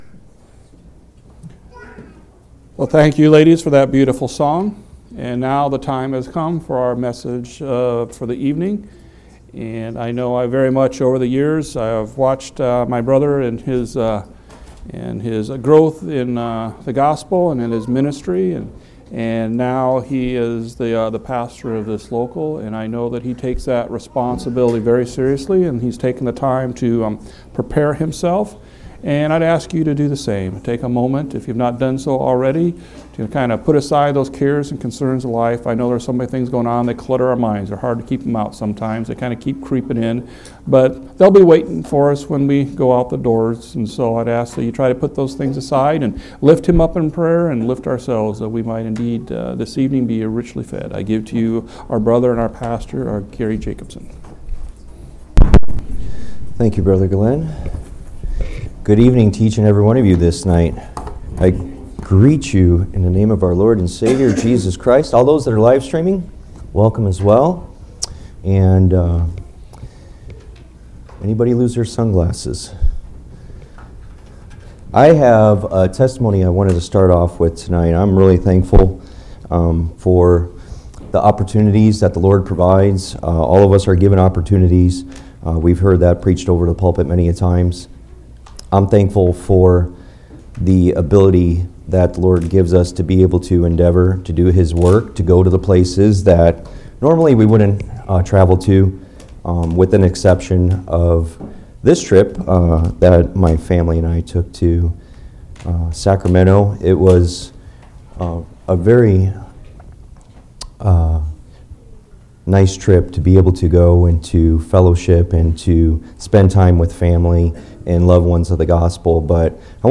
10/27/2019 Location: Phoenix Local Event